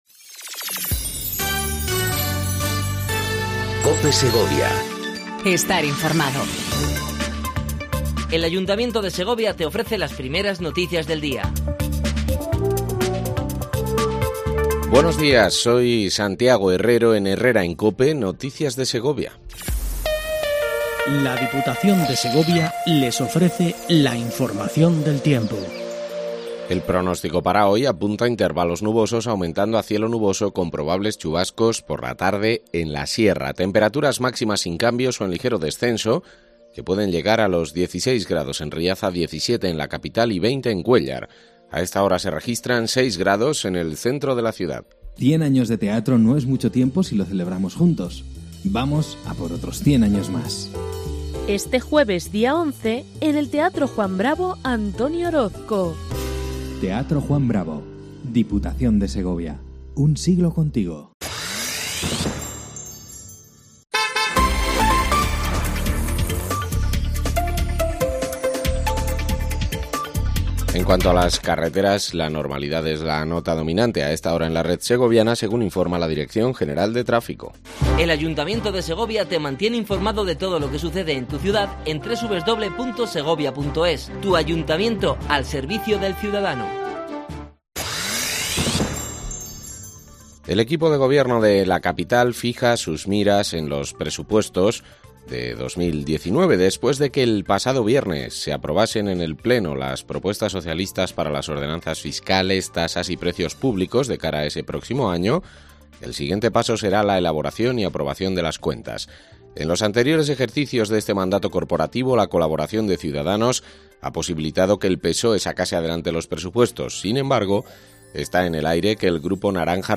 INFORMATIVO 07:55 COPE SEGOVIA 09/10/18
AUDIO: Primer informativo local en cope segovia